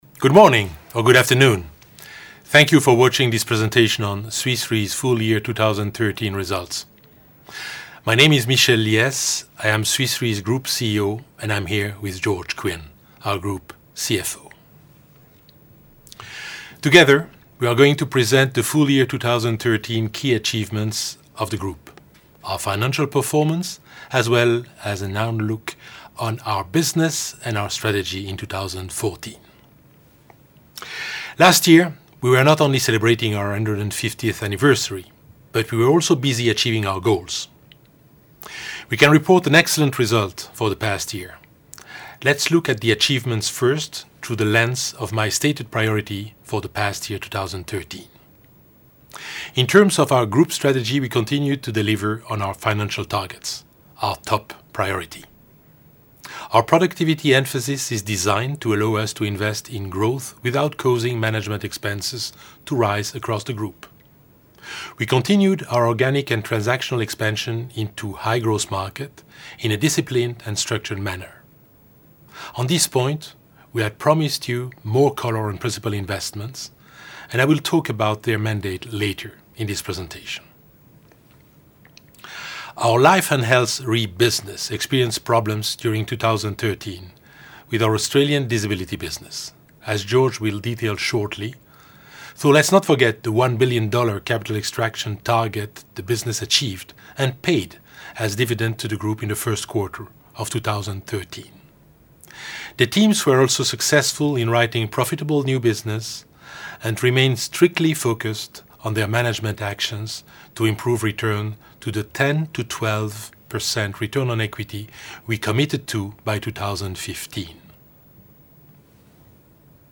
Audio of Full Year 2013 results Video Presentation